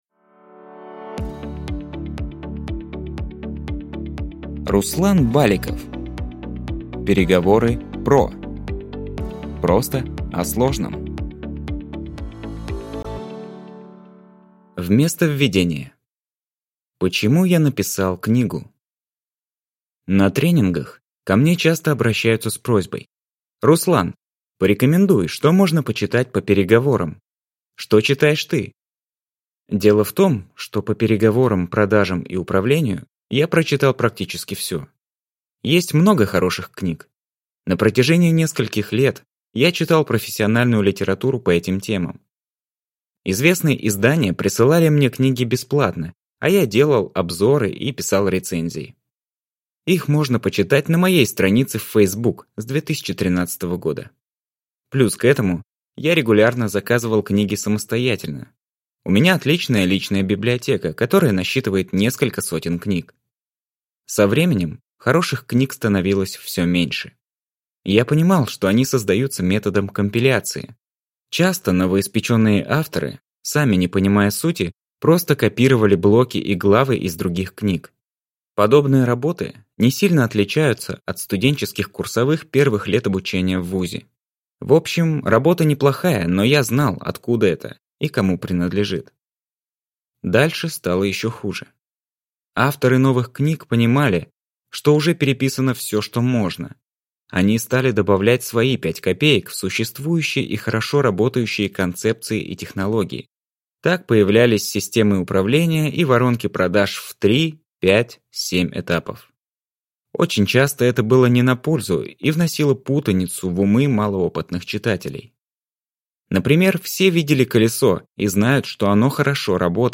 Аудиокнига Переговоры PRO. Просто о сложном | Библиотека аудиокниг